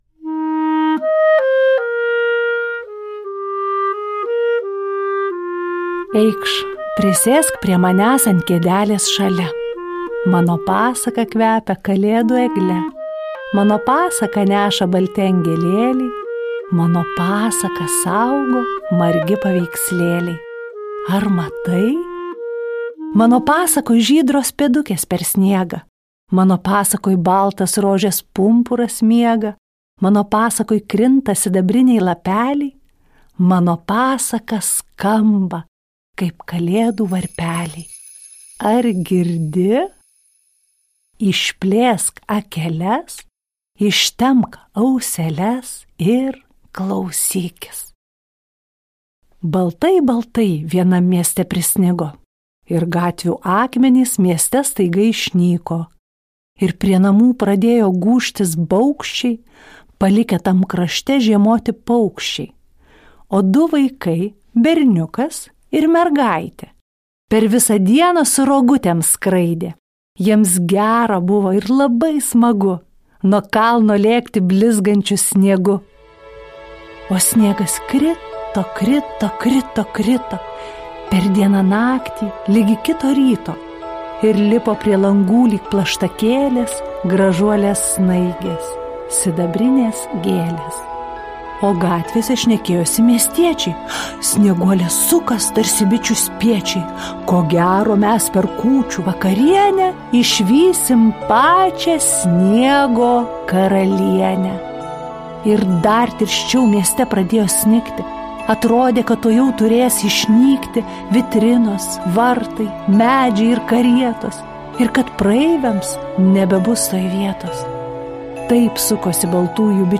Tinklalaidė įrašyta Lietuvos nacionalinės Martyno Mažvydo bibliotekos garso įrašų studijoje